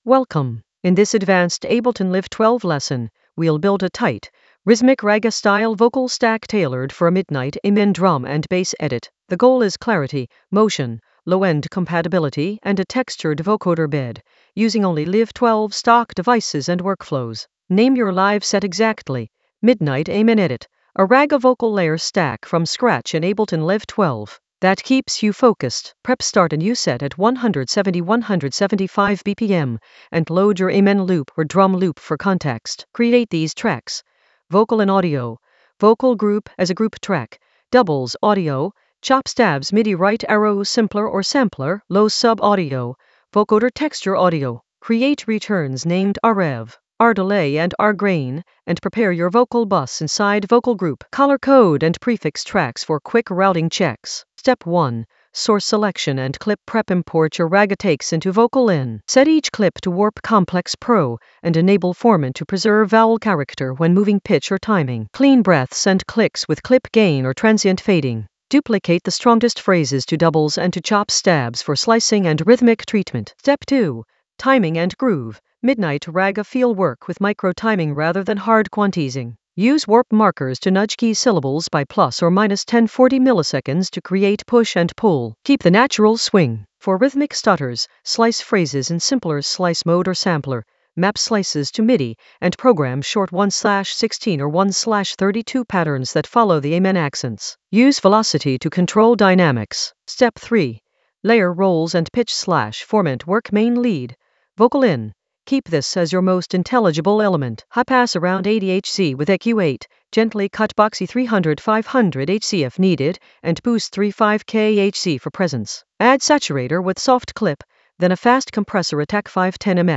An AI-generated advanced Ableton lesson focused on Midnight Amen edit: a ragga vocal layer stack from scratch in Ableton Live 12 in the Edits area of drum and bass production.
Narrated lesson audio
The voice track includes the tutorial plus extra teacher commentary.